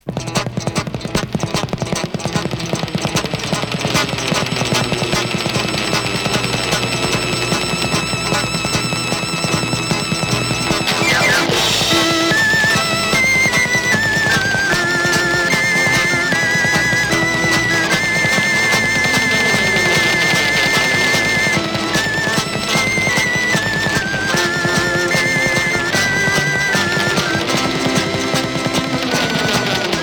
Rock instrumental Deuxième EP retour à l'accueil